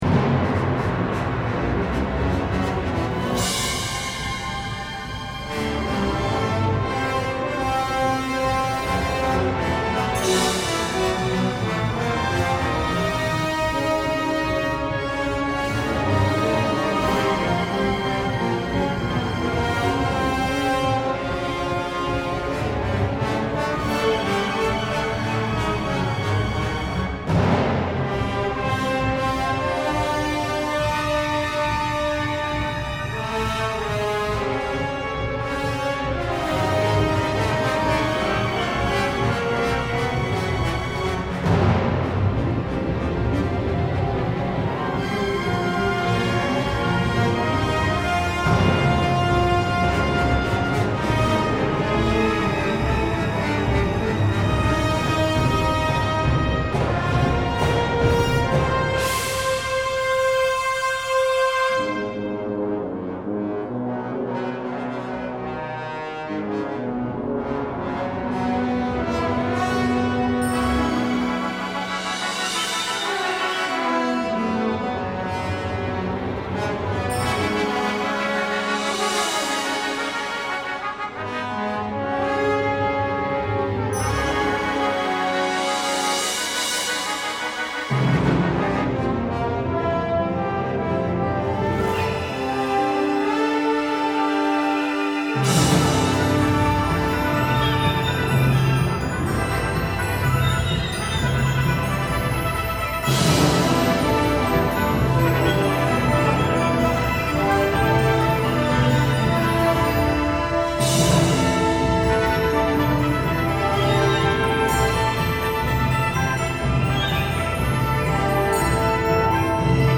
Concerto for Orchestra 管弦樂協奏曲 32 minutes